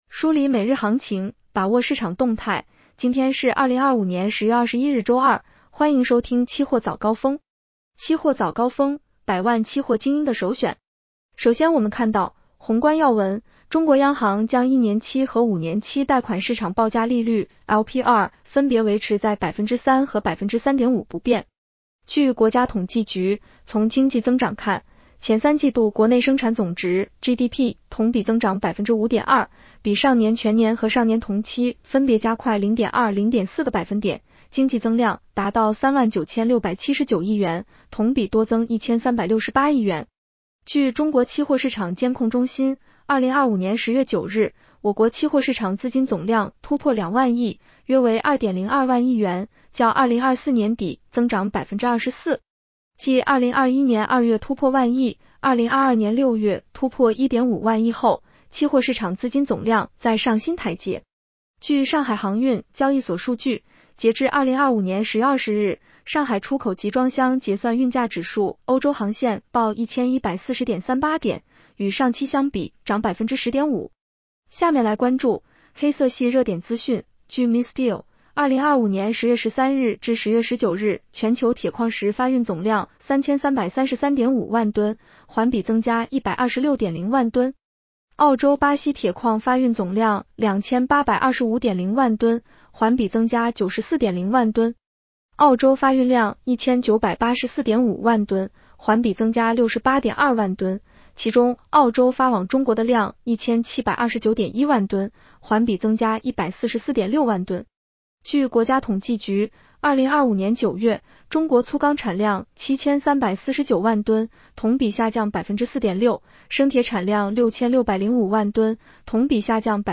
期货早高峰-音频版 女声普通话版 下载mp3 热点导读 1.